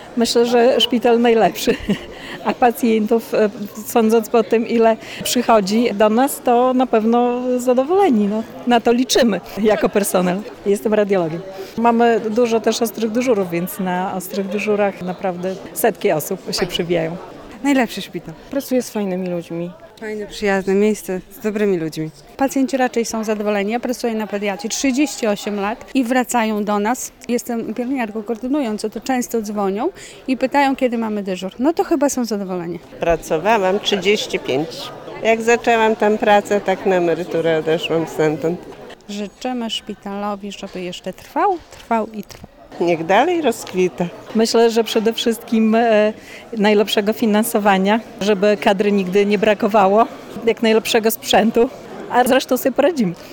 Pracownicy szpitala chwalą swoją placówkę: